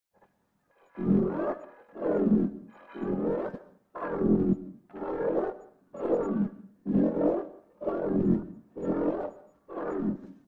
Download Robot Movement sound effect for free.
Robot Movement